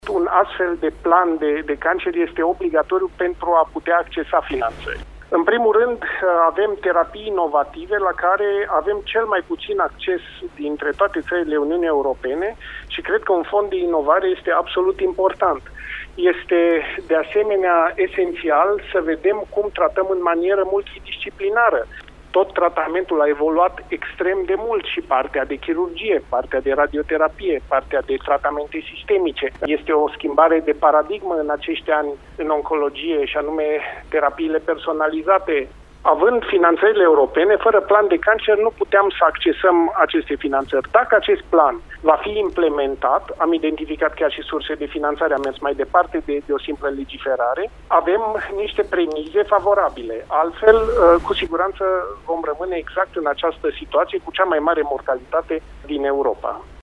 Președintele acestui grup de lucru e medicul clujean prof.dr. Patriciu Achimaș Cadariu – invitat astăzi, la Tema Zilei, la Radio Cluj: